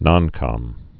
(nŏnkŏm)